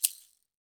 Index of /90_sSampleCDs/Roland L-CD701/PRC_Latin 2/PRC_Shakers
PRC SHAKER4.wav